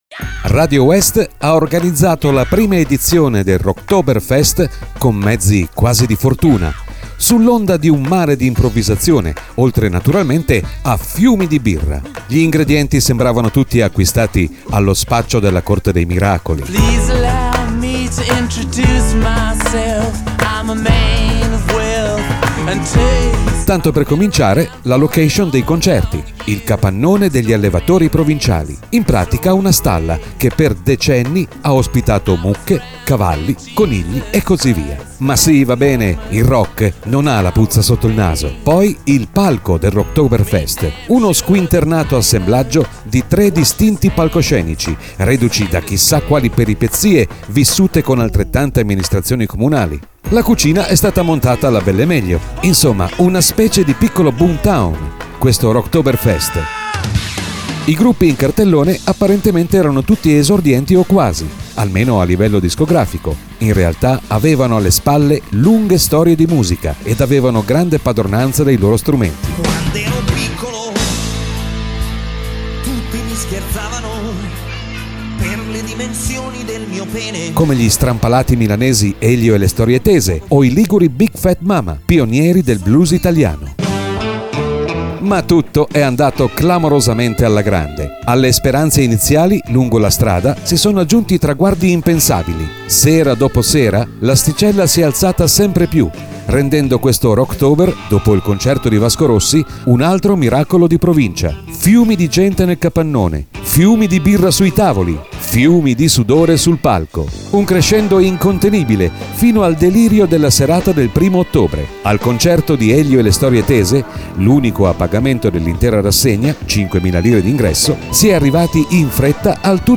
voce narrante
Rocktober_narrazione.mp3